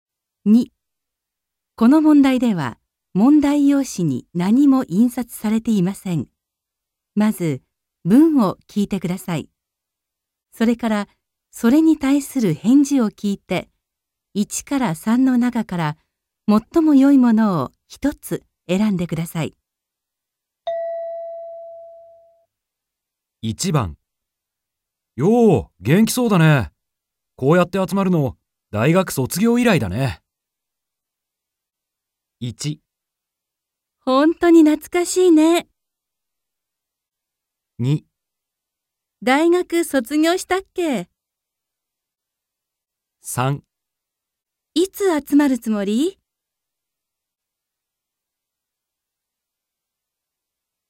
問題 4 ［聴解］